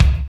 27.02 KICK.wav